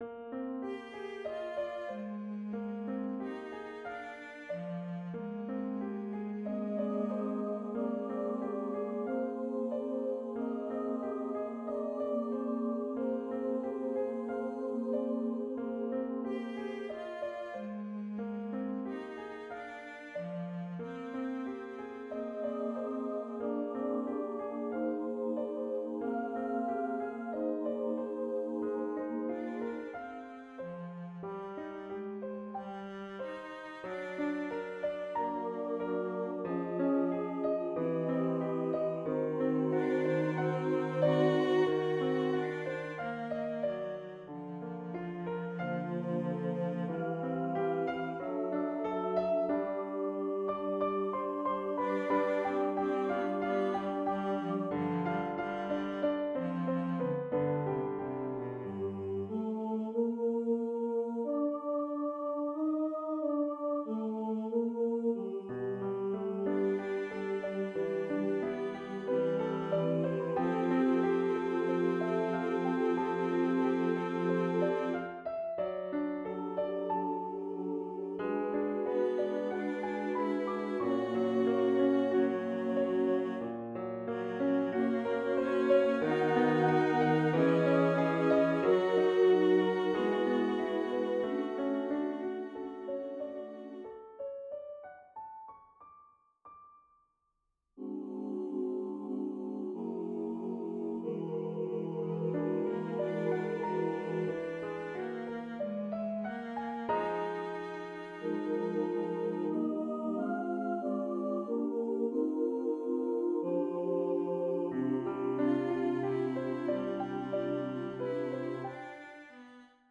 CHORAL OFFERING
This S.A.T.B. piece is a prayer of gratitude to God for that